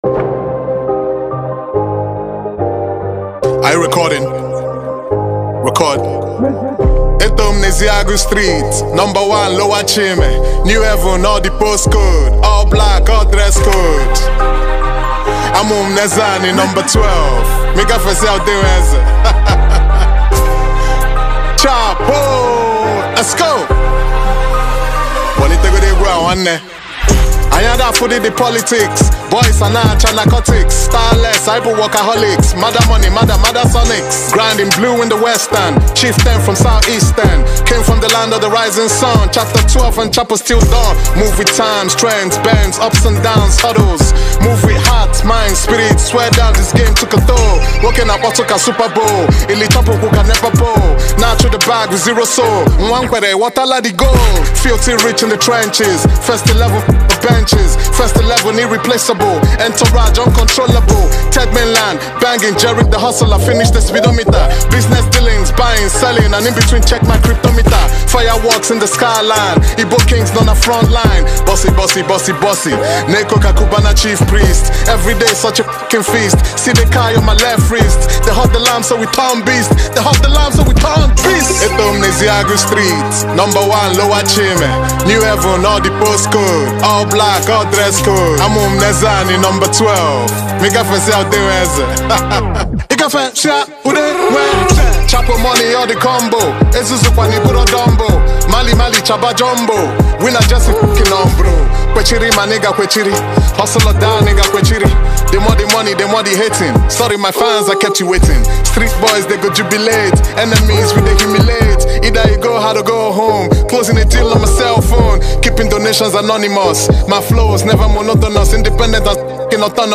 Legendary Music Rapper, Lyricist and Label Boss